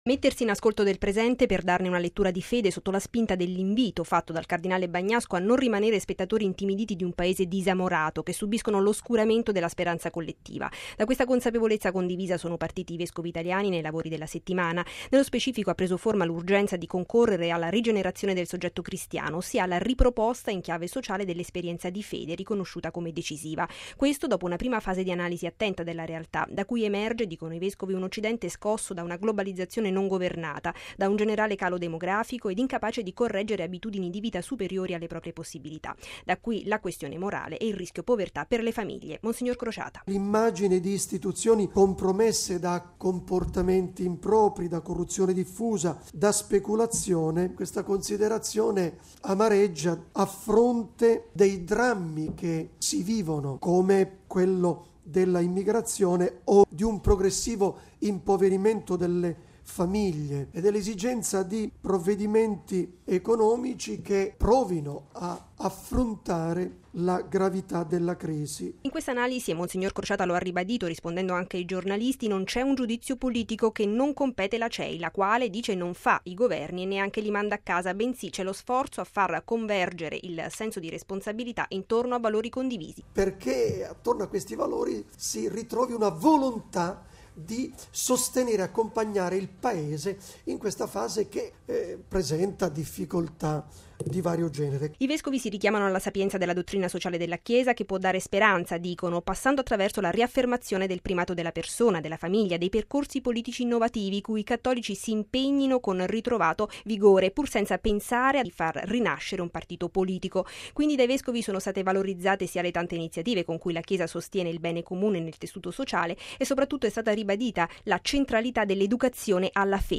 ◊   Si sono conclusi i lavori della sessione autunnale del Consiglio episcopale permanente della Cei. Questa mattina nella sede della nostra emittente la conferenza stampa finale, alla presenza del segretario generale, mons. Mariano Crociata, che ha illustrato in relazione all’analisi fatta dai vescovi in questi giorni della realtà italiana le linee guida e le priorità della Chiesa dei prossimi anni, in primo piano educazione cristiana, famiglia e formazione sacerdotale.